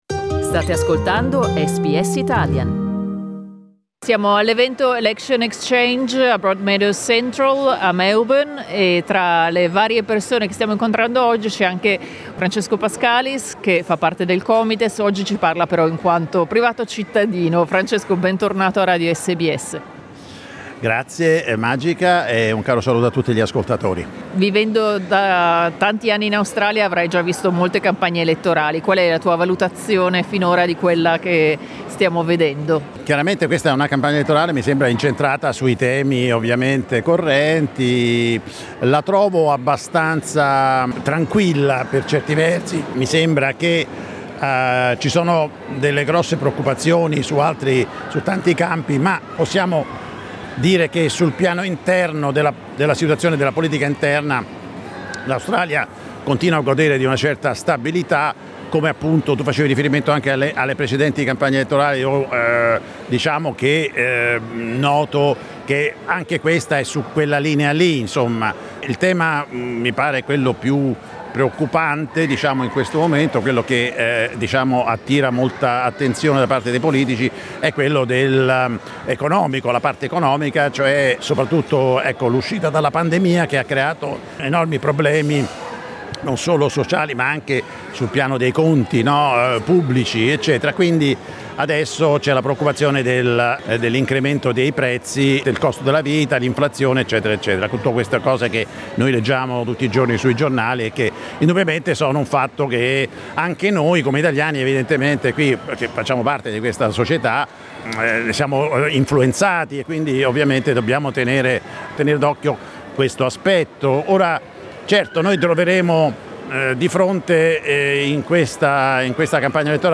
"Election Exchange" è un ciclo di incontri organizzato da SBS radio in varie città australiane, per incontrare candidate/i, esponenti delle organizzazioni comunitarie ed elettori/elettrici. Sabato 30 aprile SBS Radio ha tenuto uno degli incontri a Melbourne.